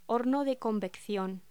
Locución: Horno de convección
voz